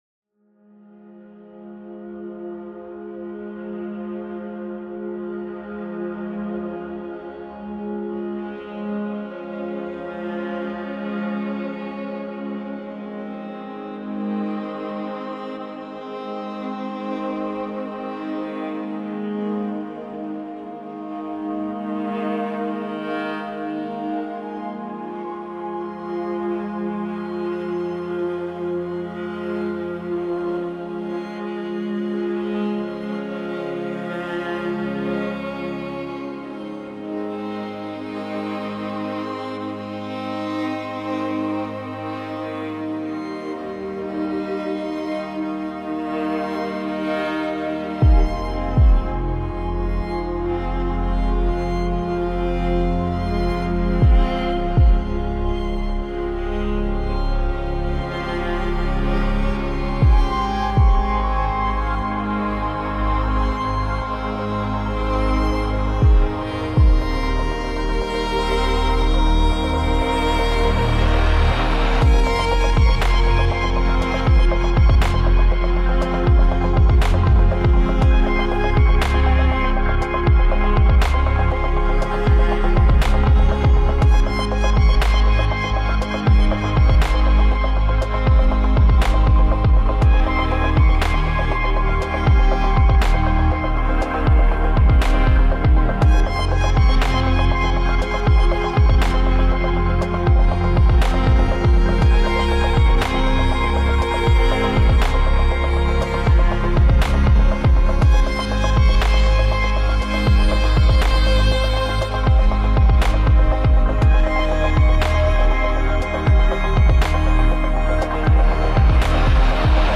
Authentic viola library
Deep, realistic sound